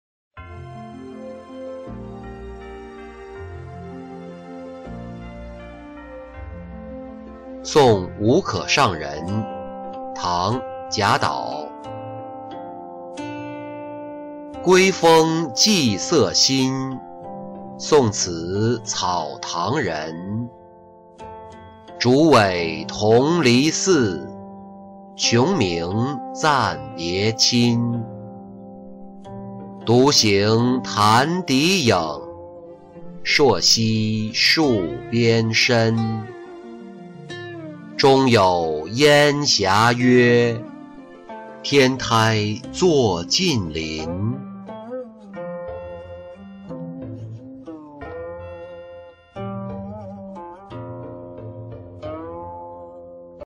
送无可上人-音频朗读